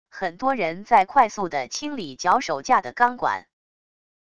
很多人在快速的清理脚手架的钢管wav下载